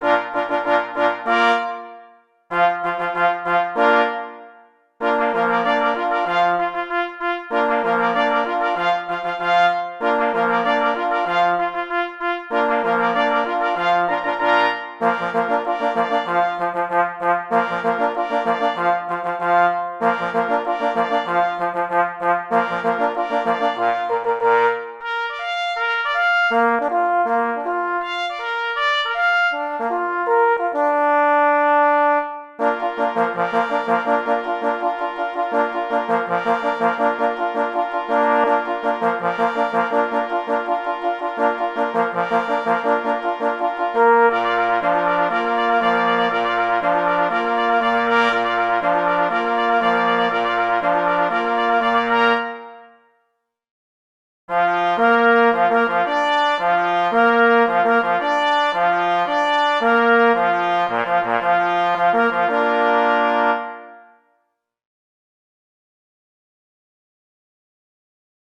na 2 plesy i 2 paforsy